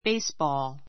béisbɔːl